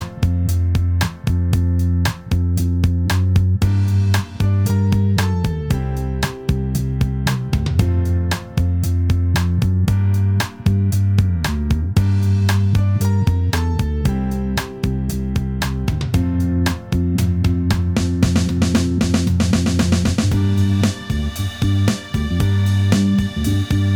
Minus Guitars Pop (1970s) 2:58 Buy £1.50